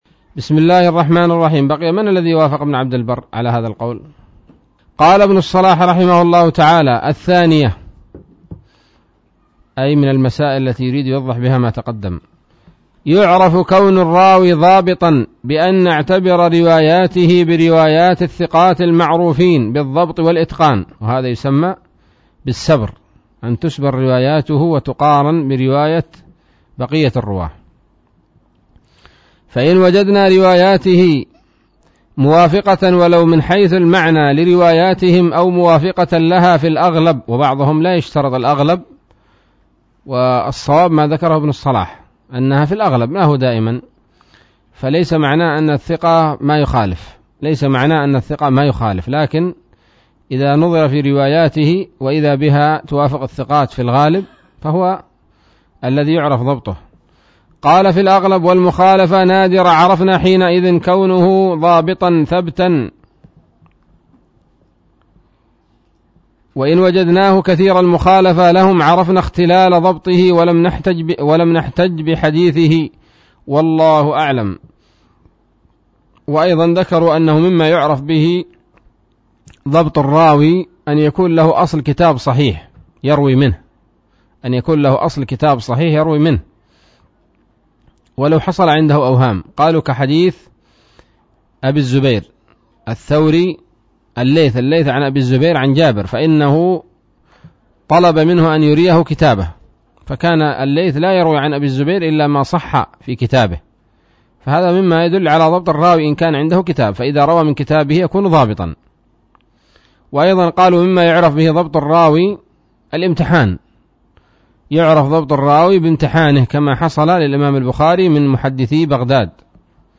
الدرس الثامن والأربعون من مقدمة ابن الصلاح رحمه الله تعالى